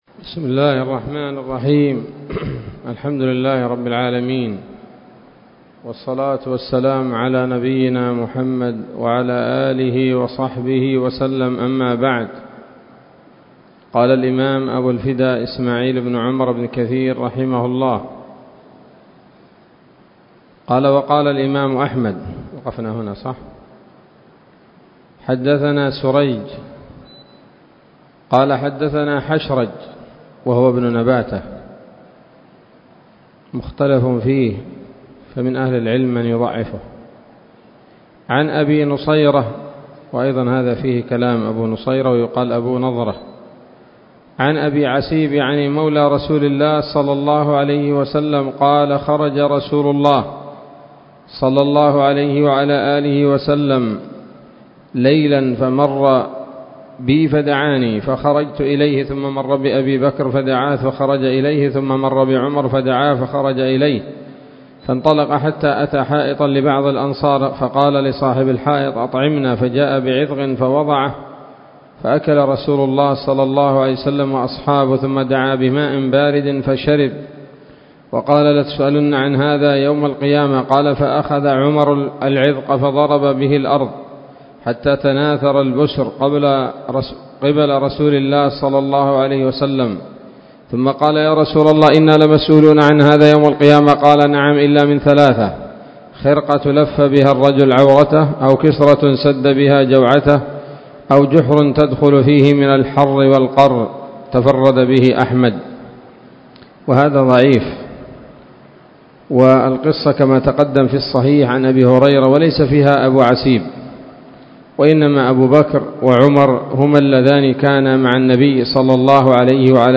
الدرس الثالث من سورة التكاثر من تفسير ابن كثير رحمه الله تعالى